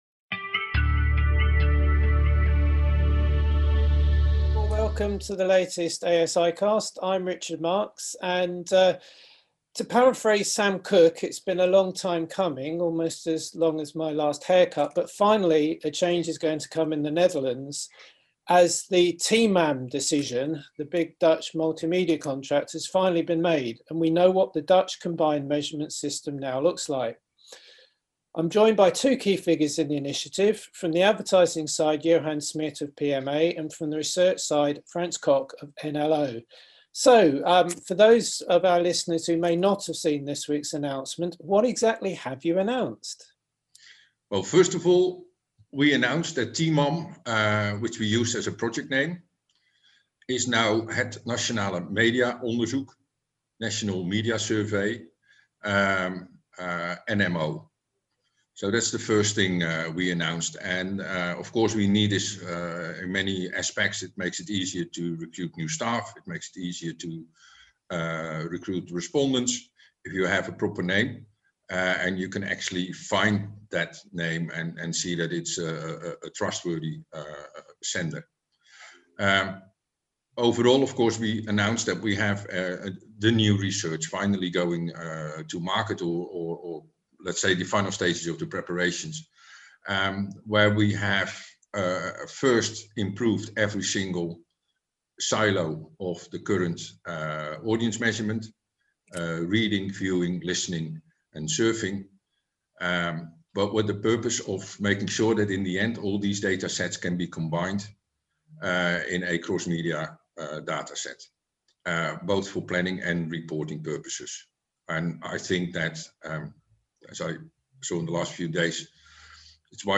The interview seeks to look at the various elements of this important new service (claimed by Kantar and Ipsos to be ‘the world’s first true cross-media total audience measurement solution’) and determine what is being done and by whom.